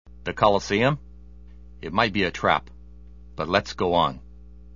If you thought Resident Evil was the apex of bad voice acting, think again.